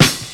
• 00's Natural Hip-Hop Acoustic Snare A# Key 31.wav
Royality free snare one shot tuned to the A# note. Loudest frequency: 2725Hz
00s-natural-hip-hop-acoustic-snare-a-sharp-key-31-Hrf.wav